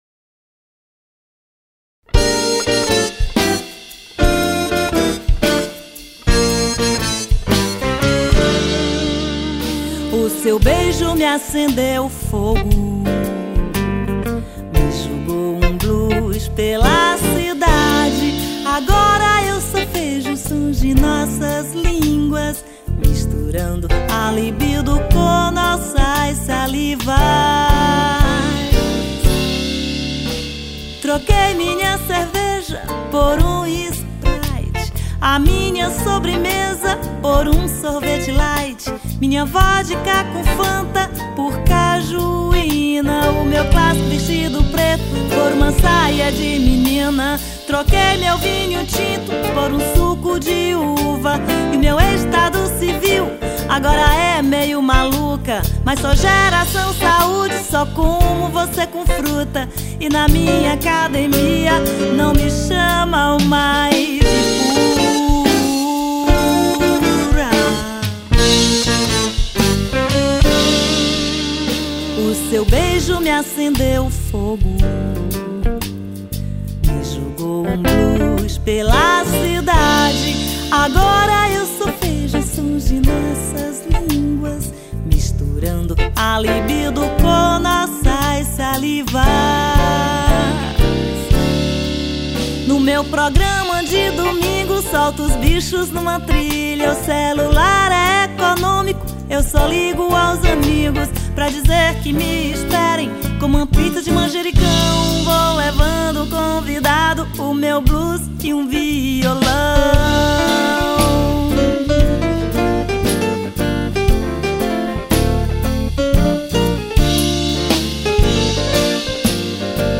1576   03:40:00   Faixa:     Bossa nova
Baixo Elétrico 6
Bateria, Pandeiro
Guitarra
Teclados